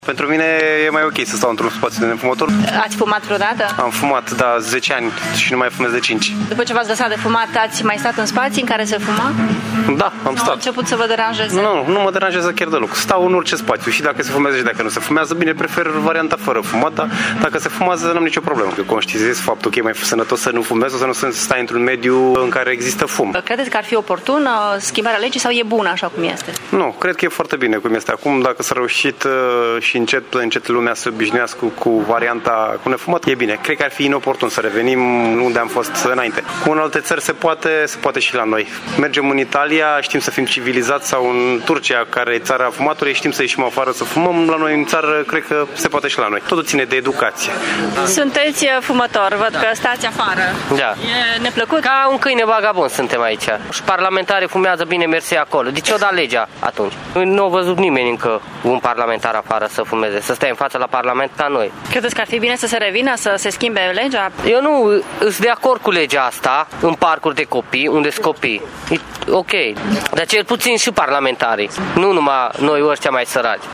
Legea nu ar trebui modificată, spun atât fumătorii cât și nefumătorii, însă ea trebuie respectată de toată lumea: